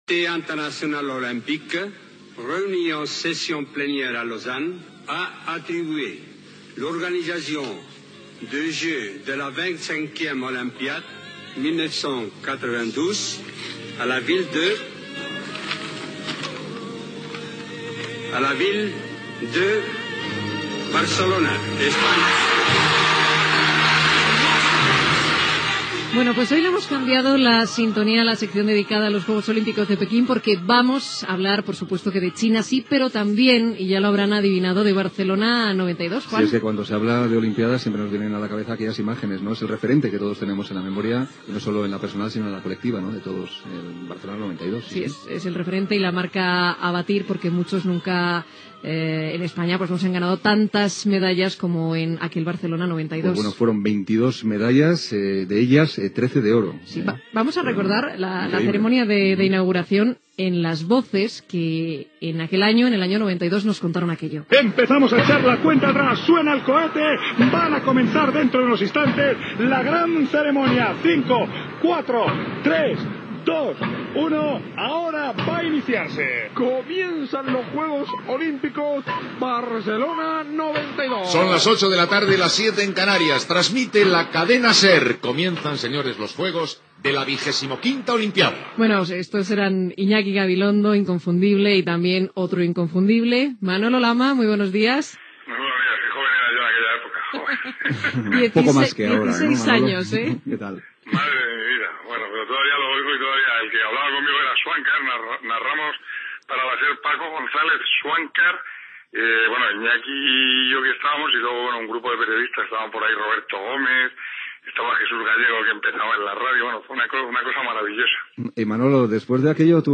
Paraules de Juan Antonio Samaranch anunciant que Barcelona serà la seu dels Jocs Olímpics de 1992. Els periodistes Manolo Lama i Olga Viza recorden la seva feina als Jocs Olímpics de Barcelona. Aposta de quantes medalles guanyarà Espanya als Jocs Olímpics de Pequín